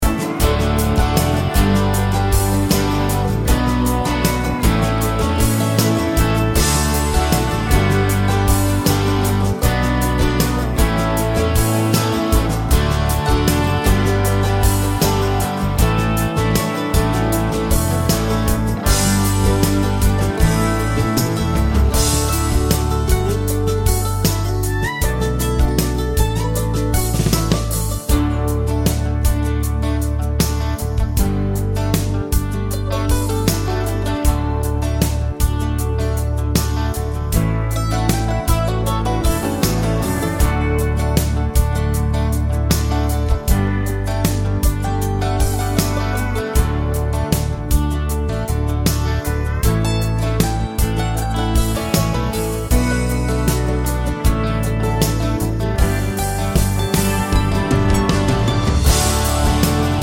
no Backing Vocals Country (Male) 3:43 Buy £1.50